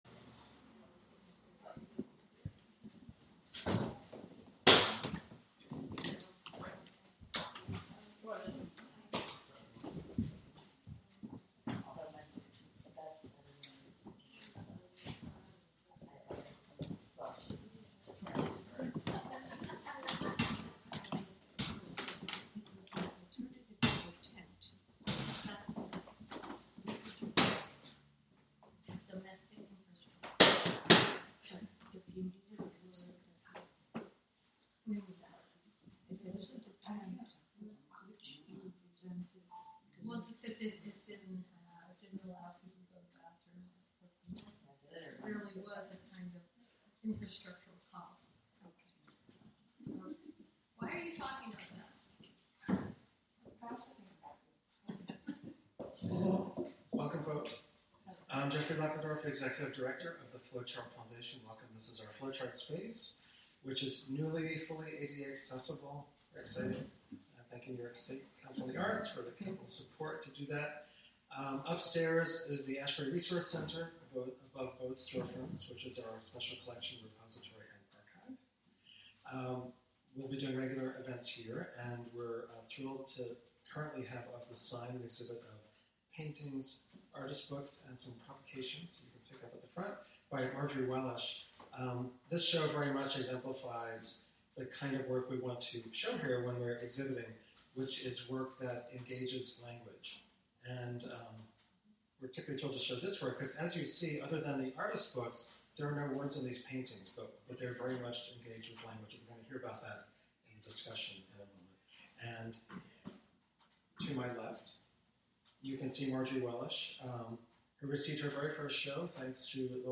Live from The Flow Chart Foundation